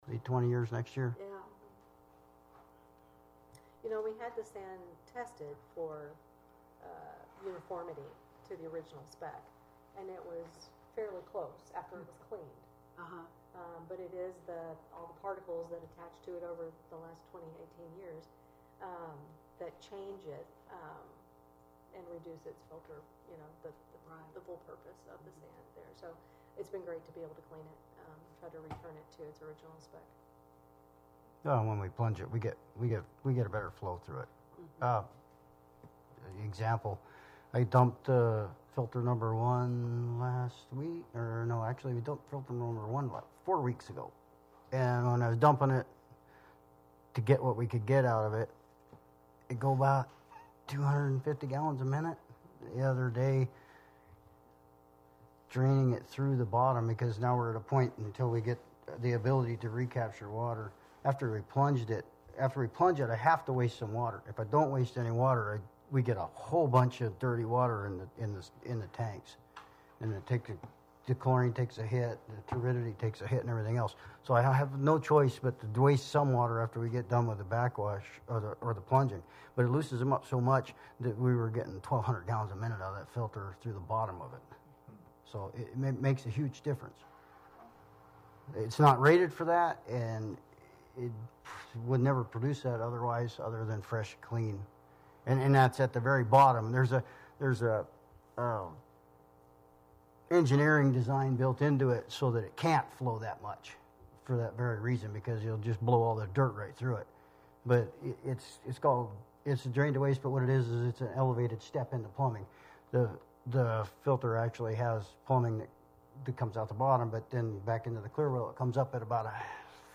Wrangell Borough Assembly met for a regular meeting on May 22, 2018.